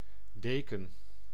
Ääntäminen
IPA: [de.kǝn]
IPA: /ˈdeːkə(n)/